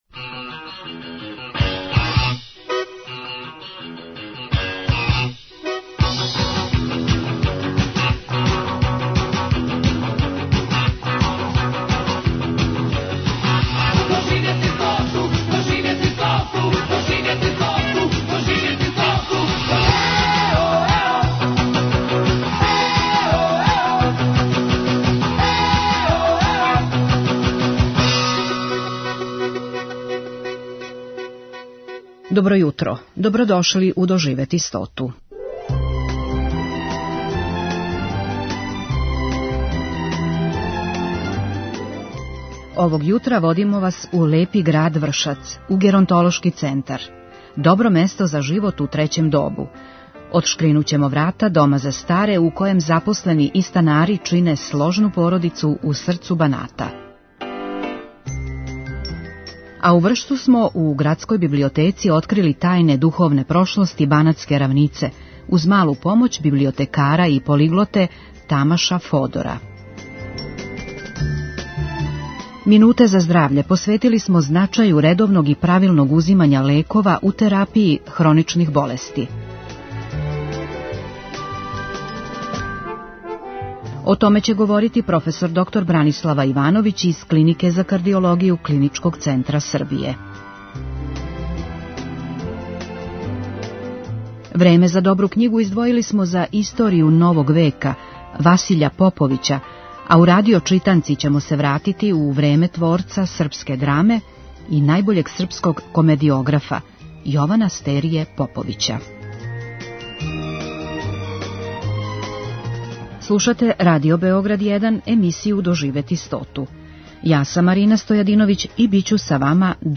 Емисија "Доживети стоту" Првог програма Радио Београда већ двадесет четири године доноси интервјуе и репортаже посвећене старијој популацији. У разговорима с истакнутим стручњацима из области социјалне политике, економије, медицине, културног, јавног и спортског живота, емисија се бави свим аспектима живота трећег доба.